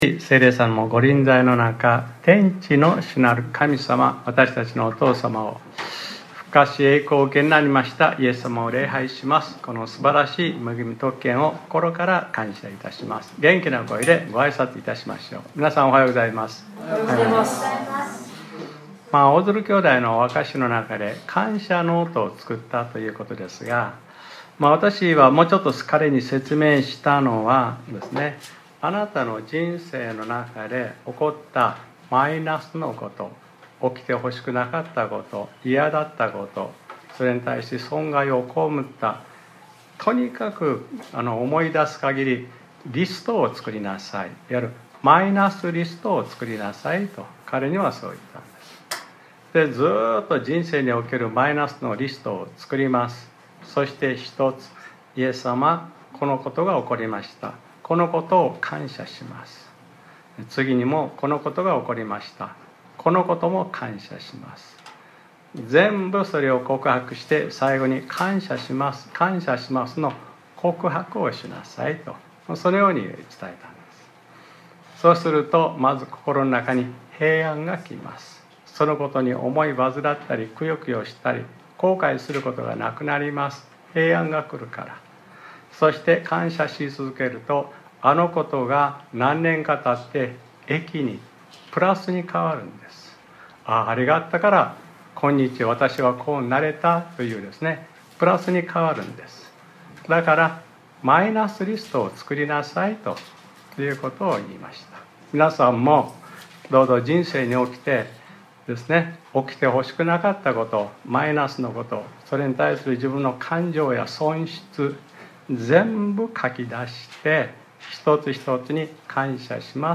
2025年01月26日（日）礼拝説教『 イエスに叫びなさい 』 | クライストチャーチ久留米教会